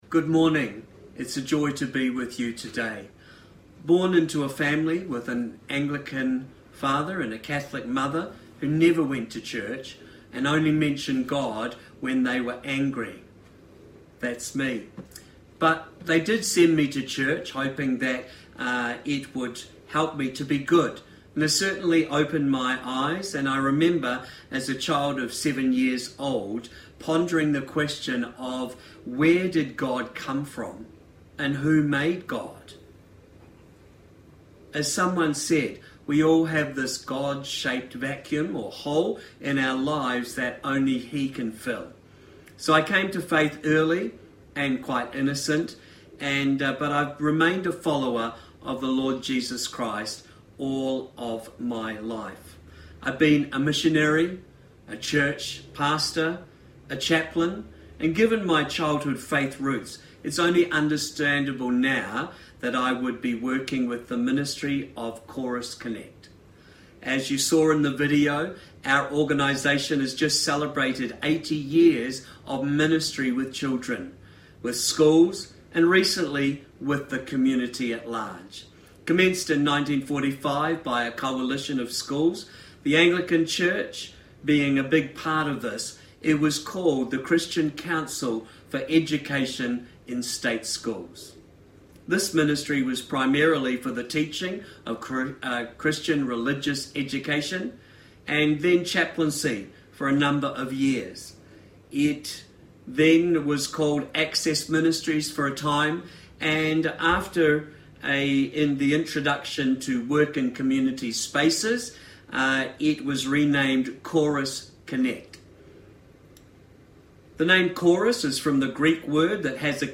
Sermon
Guest Speaker